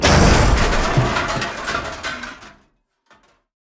crash2.ogg